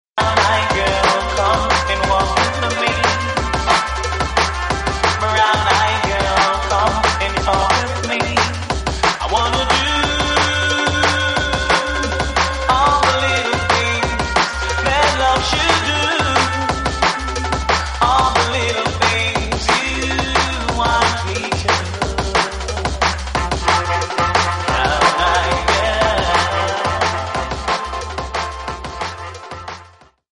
TOP > Vocal Track